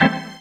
ORGAN-17.wav